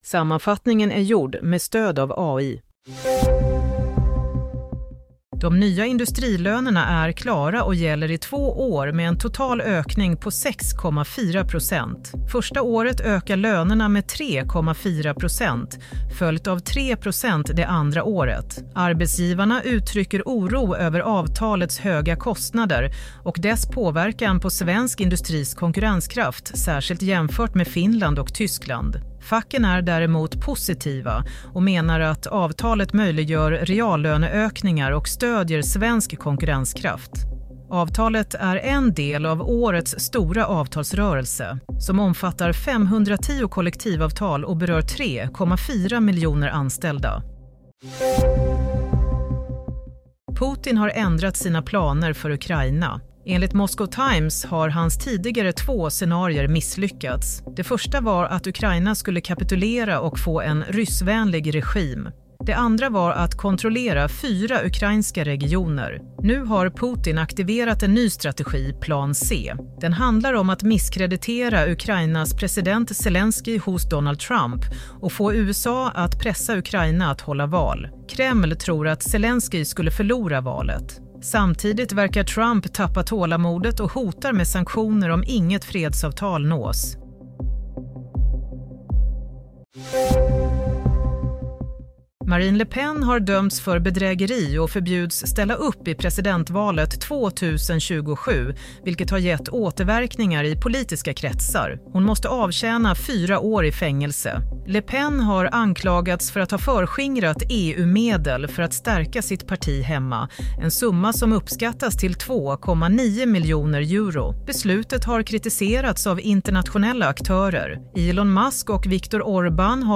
Play - Nyhetssammanfattning – 1 april 07:00